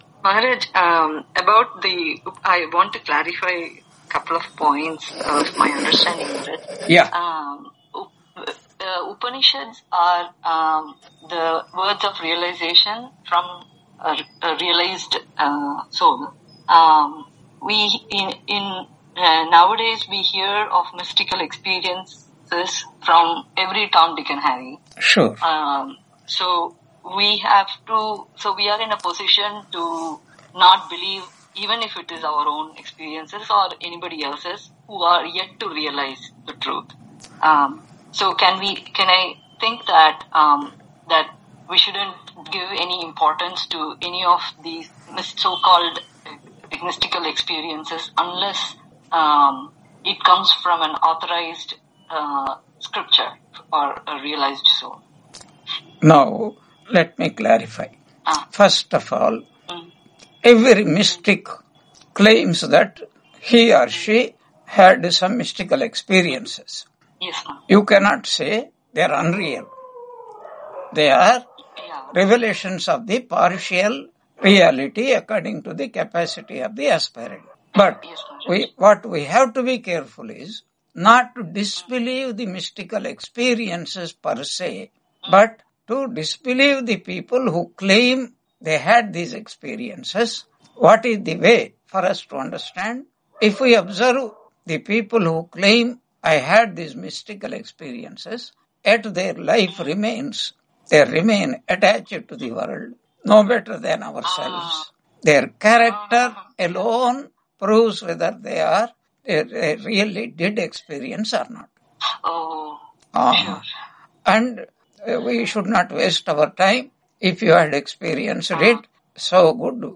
Brihadaranyaka Upanishad Introduction Lecture 06 on 08 February 2026 Q&A - Wiki Vedanta